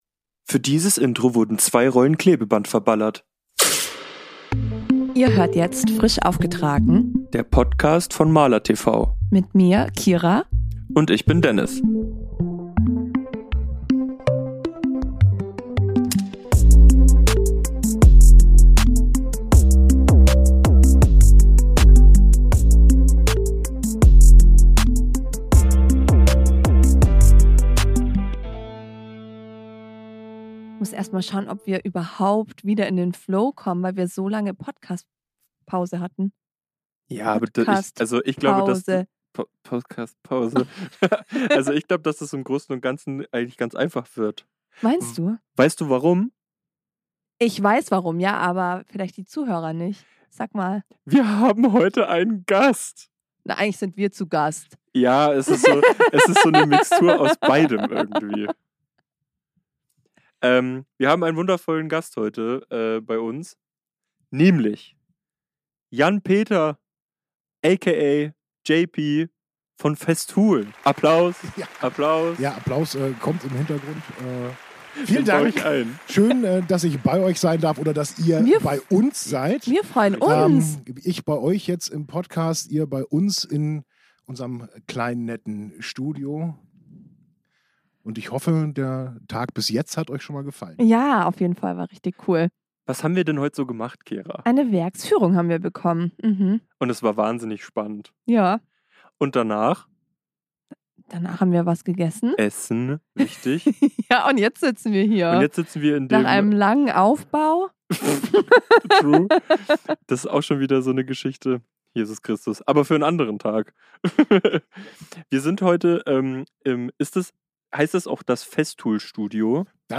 Heute waren wir bei Festool in Wendlingen zu Gast.